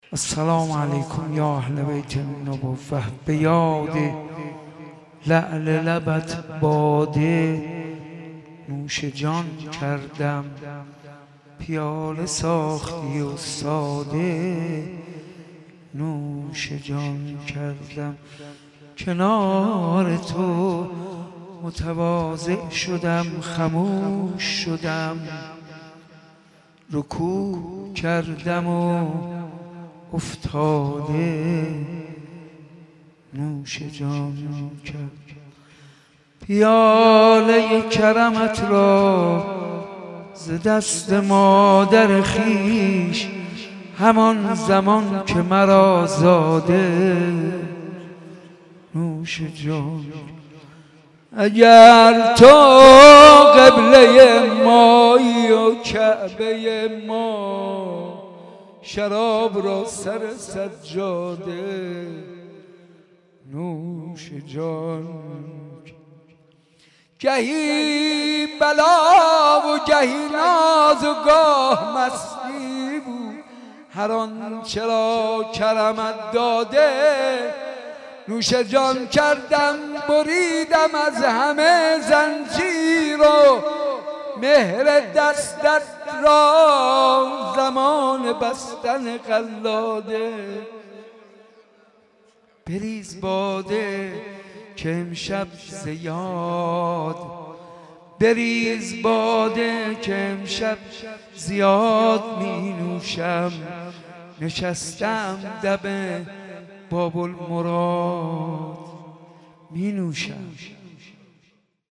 عقیق: مراسم جشن میلاد امام جواد (ع) با حضور جمعی از محبین اهل بیت(ع) در حسینیه بیت الزهرا(س) برگزار شد.